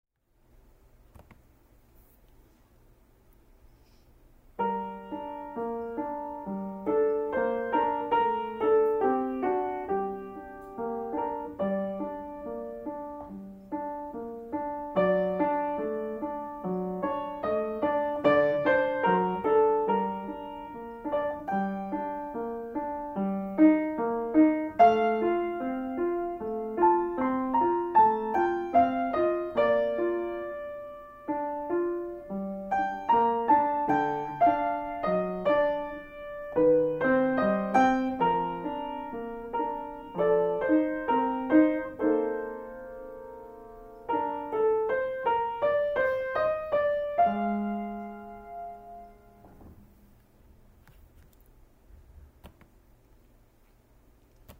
Sonata in C, K545, Andante movement, measure 33 thru 40. While playing, the character I am imagining is a lover, alone, expressing longing for and memories of someone they can no longer be with.
Mozart work in progress.mp3